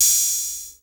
HAT 110 OHH.wav